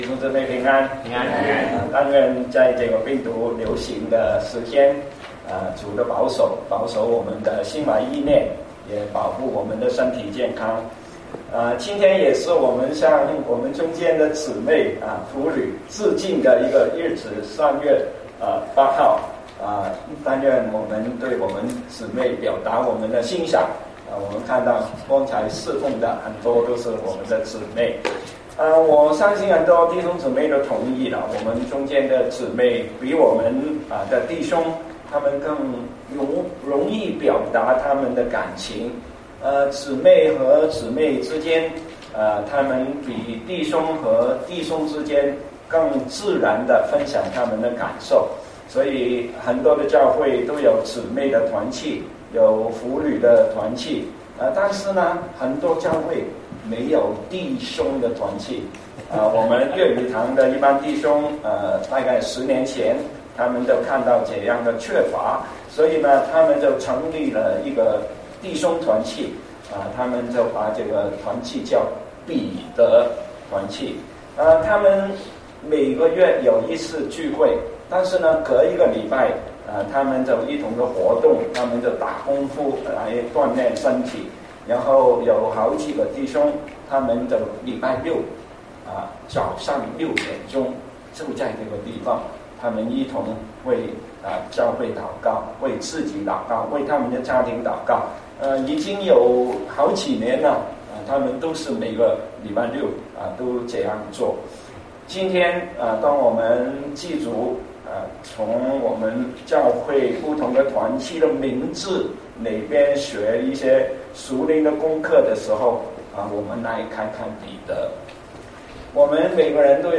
牛頓國語崇拜